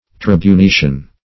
Tribunitian \Trib`u*ni*tian\, a. [L. tribunicius, tribunitius: